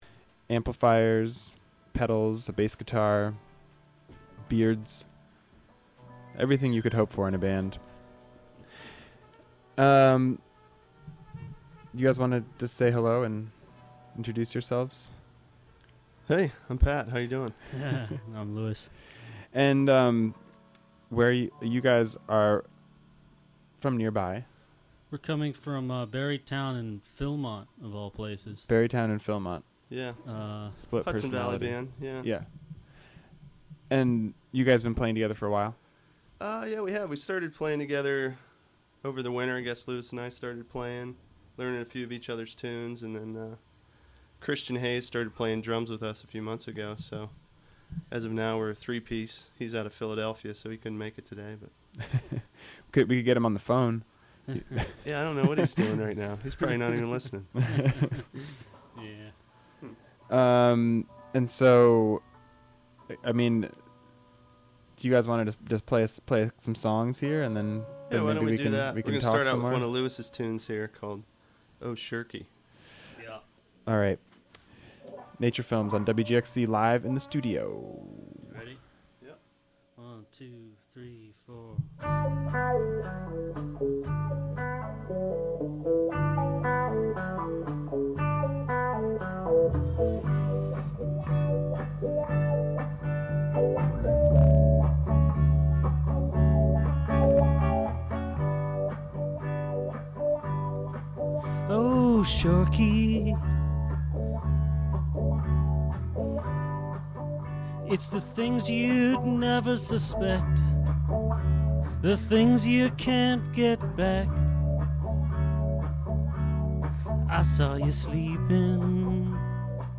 Hudson Valley-based duo